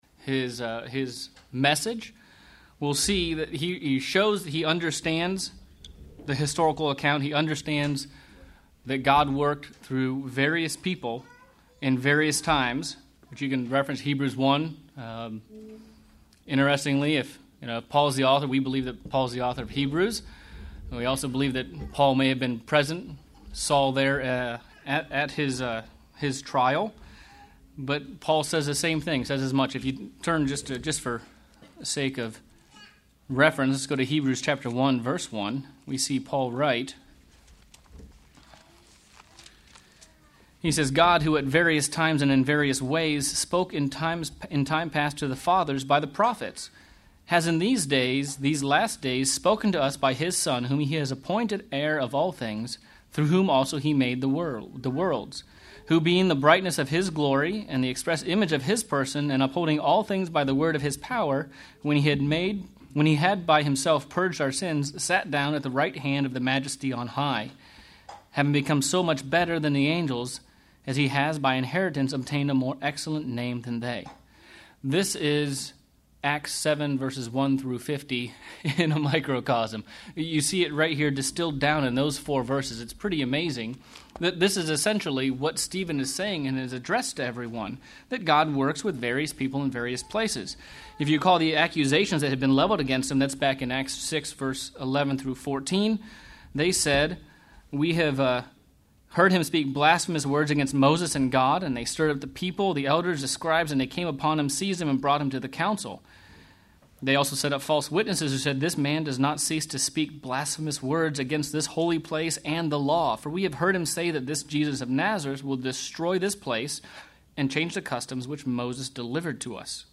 Bible Study: Acts of the Apostles - Chapter 7:34 - 8:6